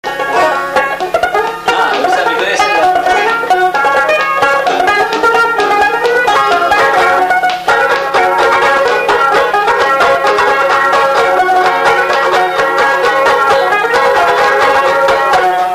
Polka
Instrumental
danse : polka
Pièce musicale inédite